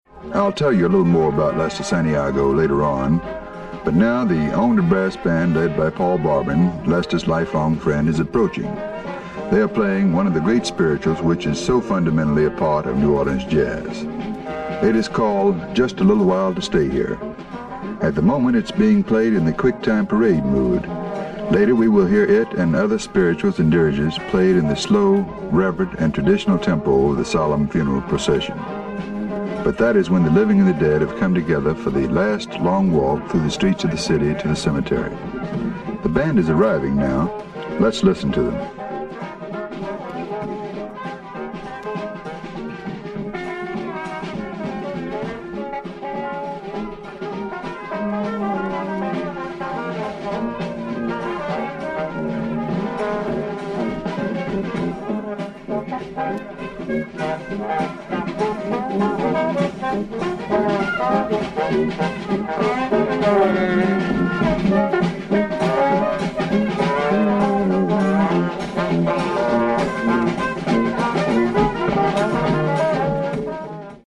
Narration by: